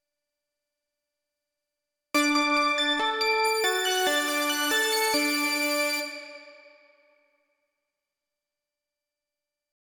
140 BPM
Fanfare — celebrate victory
D-major triadic brass stabs over sustained horn bass
victory-horn.mp3